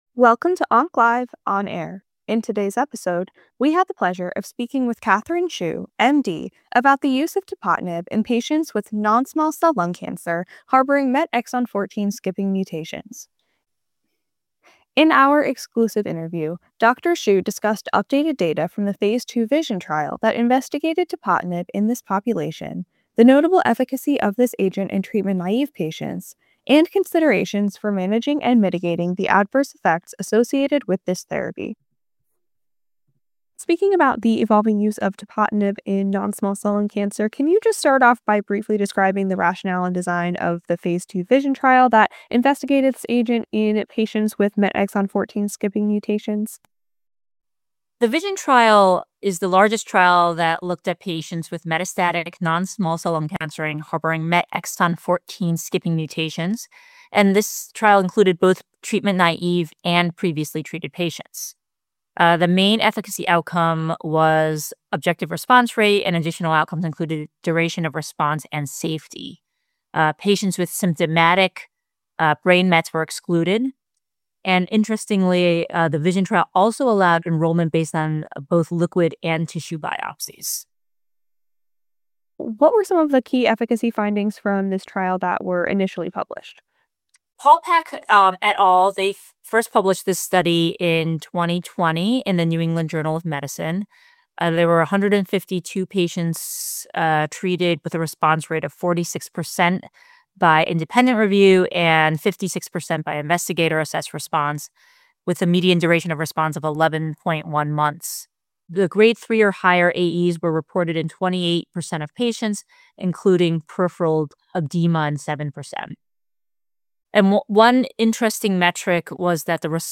In OncLive® On Air, you can expect to hear interviews with academic oncologists on the thought-provoking oncology presentations they give at the OncLive® State of the Science Summits. The topics in oncology vary, from systemic therapies, surgery, radiation therapy, to emerging therapeutic approaches in a particular type of cancer.